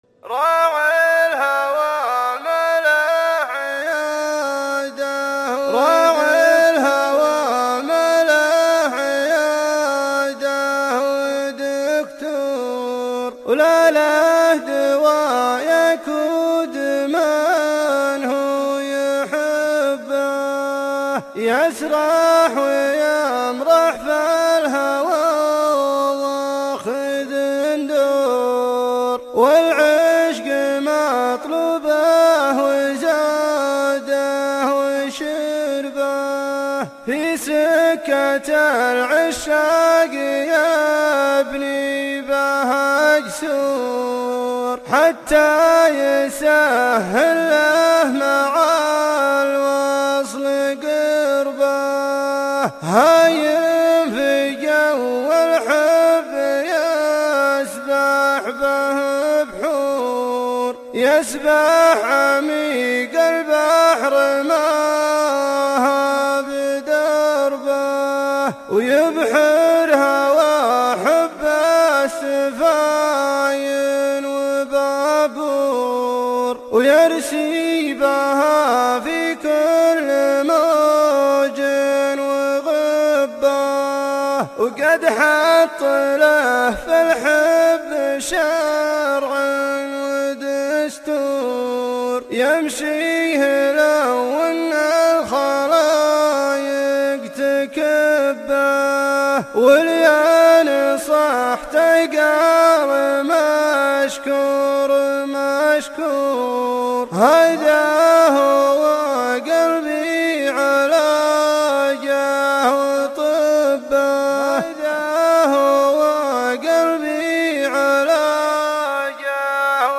الشيلات -> شيلات منوعه 7